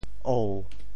“芋”字用潮州话怎么说？
芋 部首拼音 部首 艹 总笔划 6 部外笔划 3 普通话 yù 潮州发音 潮州 ou7 文 中文解释 芋〈名〉 (形声。